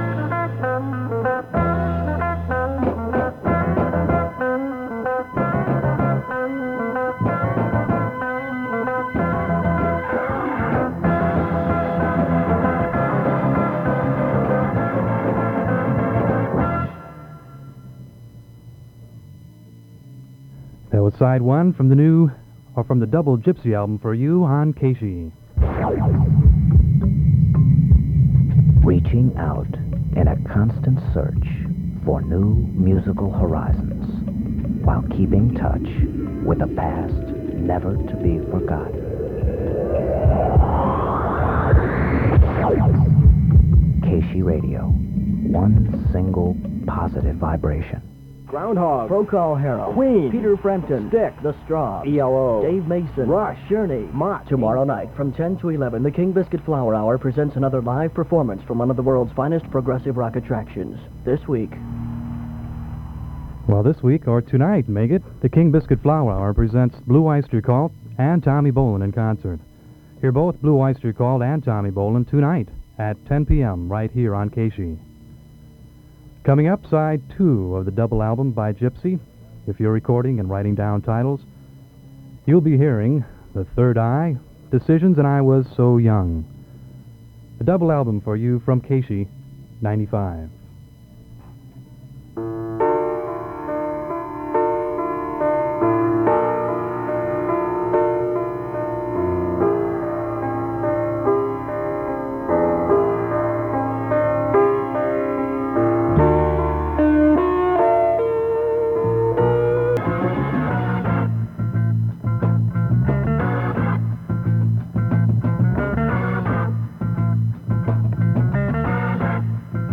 KSHE Unknown Announcer aircheck · St. Louis Media History Archive
Original Format aircheck